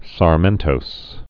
(sär-mĕntōs)